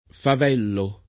favello [ fav $ llo ]